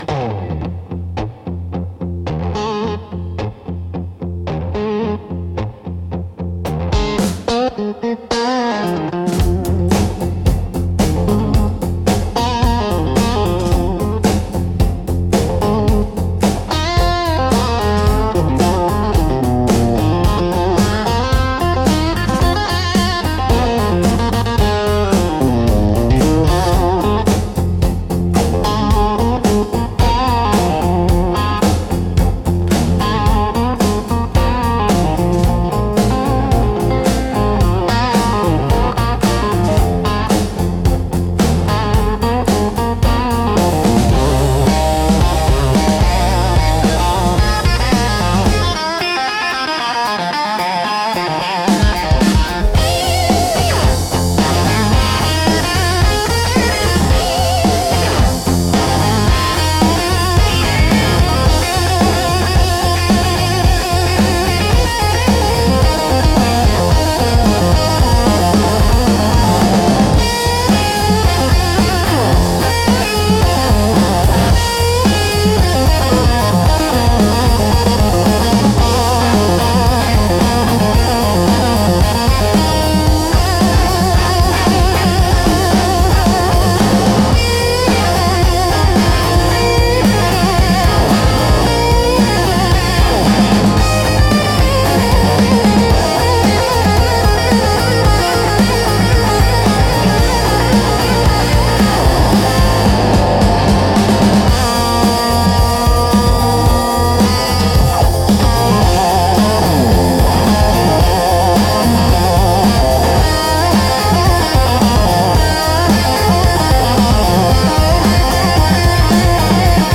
Instrumental - The Hum Before the Storm